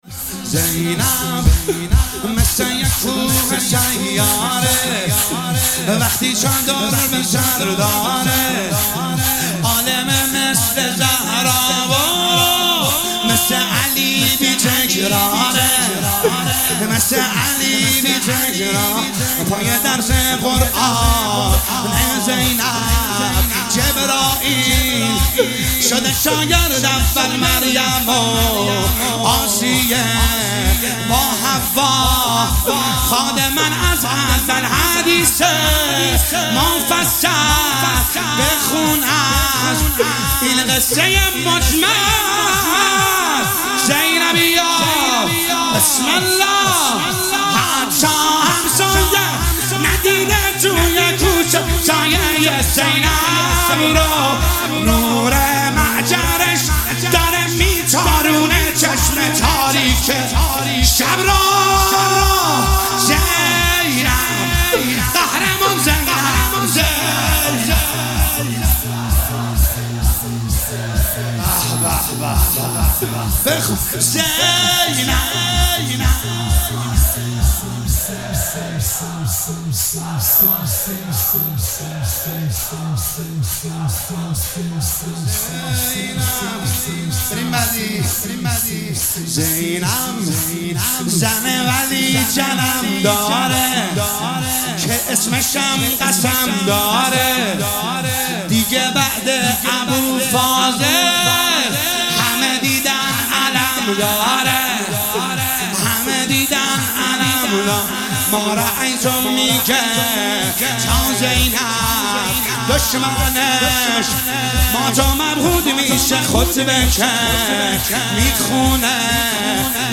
شب عاشورا محرم1401 - شور